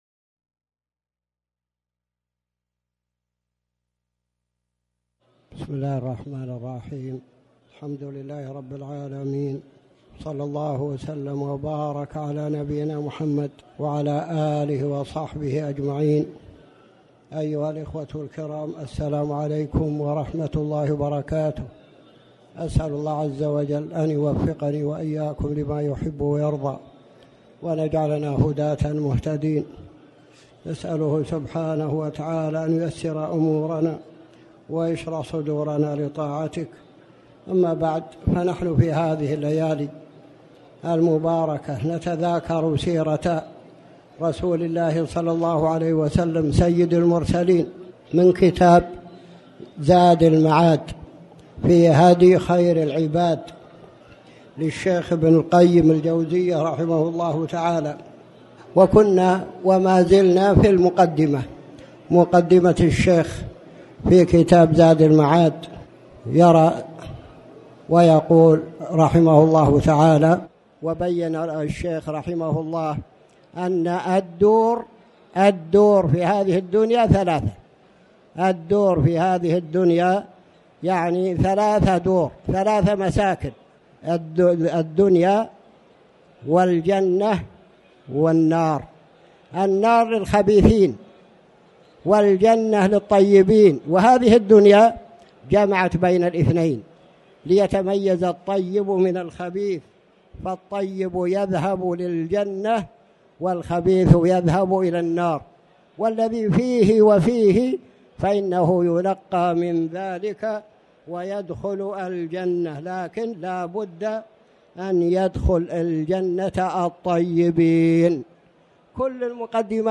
تاريخ النشر ٢ رجب ١٤٣٩ هـ المكان: المسجد الحرام الشيخ